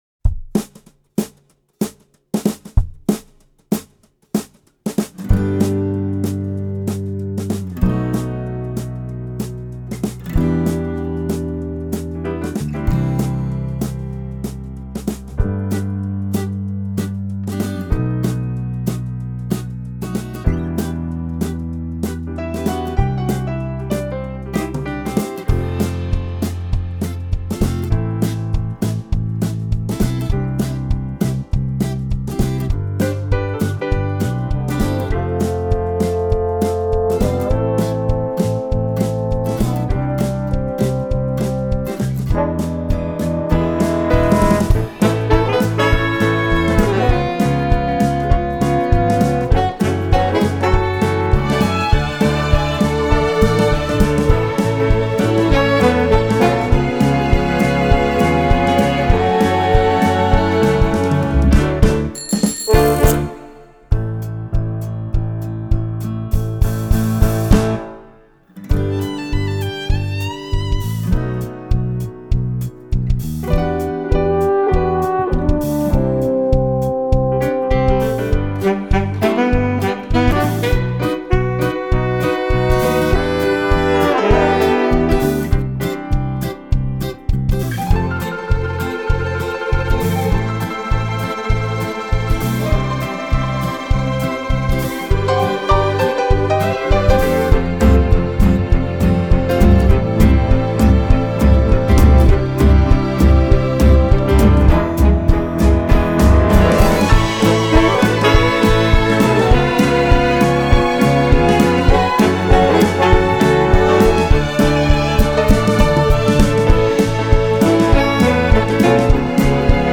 Original London Cast Recording